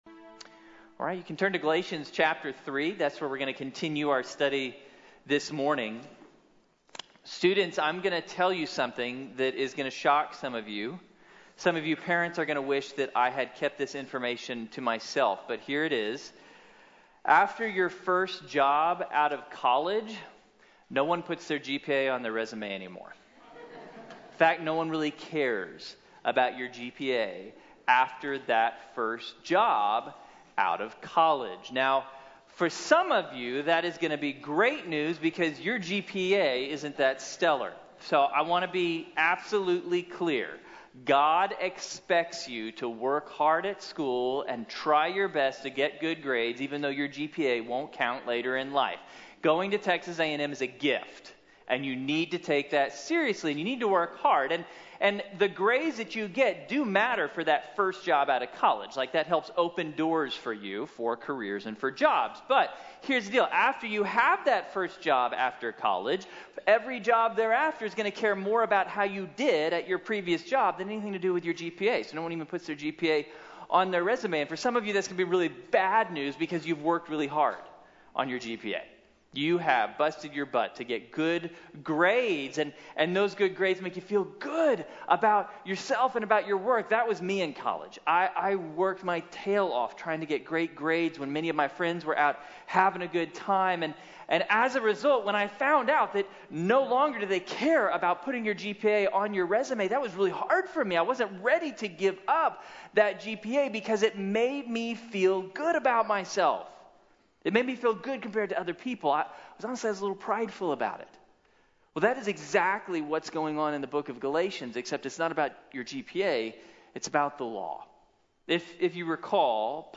Libre de la Ley | Sermón | Iglesia Bíblica de la Gracia